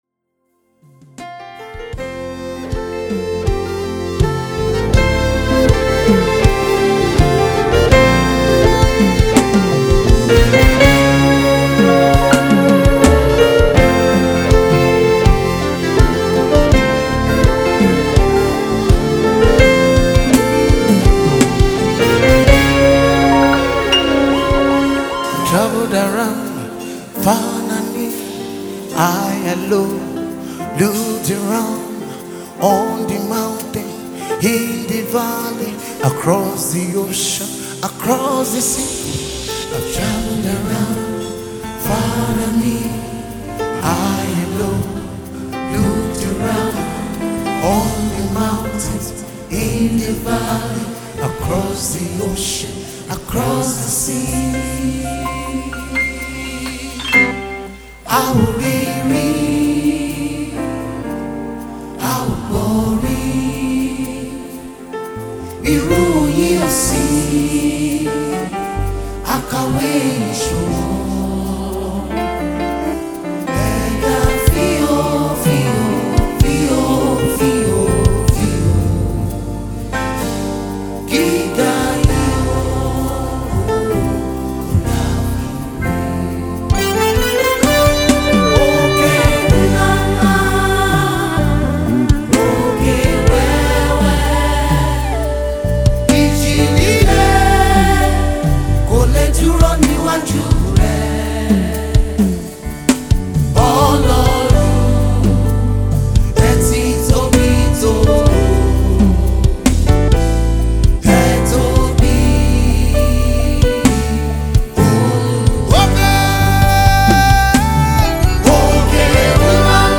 live concert